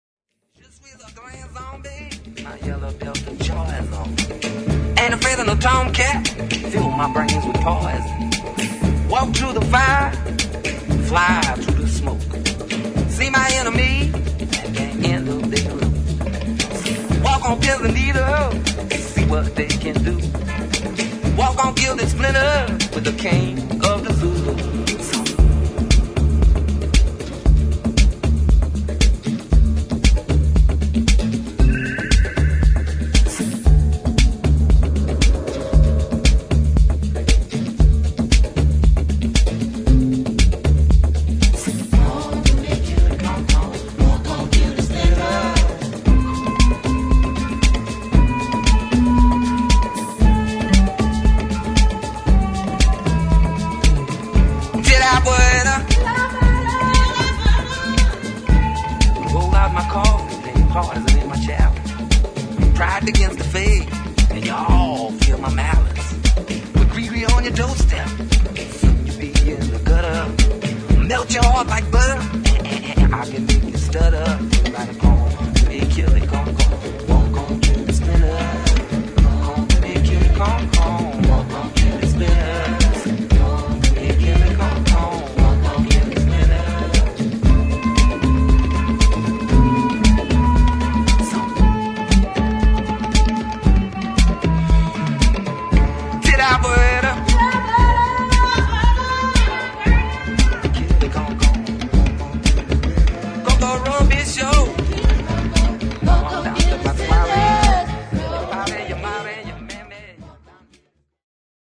[ HOUSE / EDIT ]